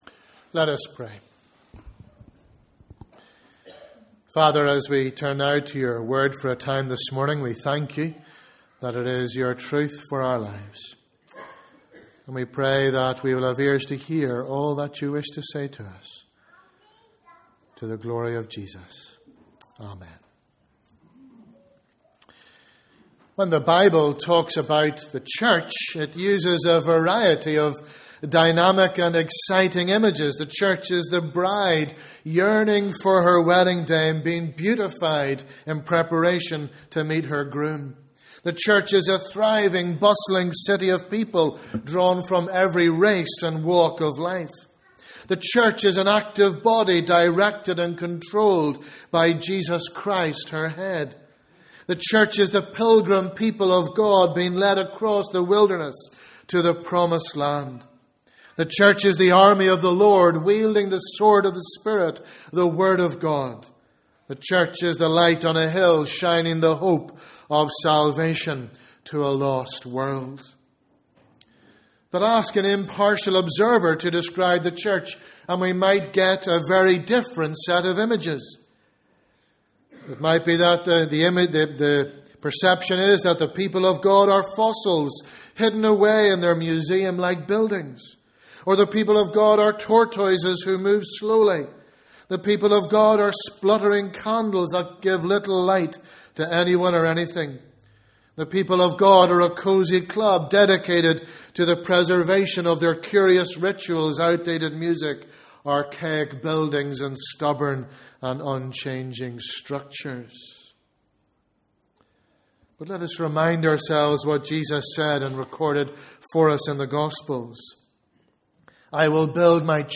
Bible Reading: Acts 2 v 40-47 Morning Service: Sunday 27th October